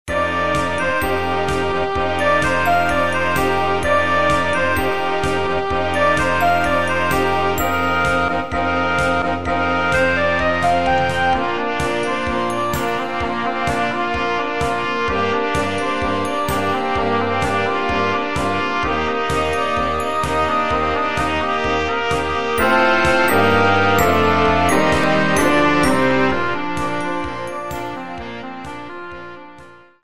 Rozrywkowa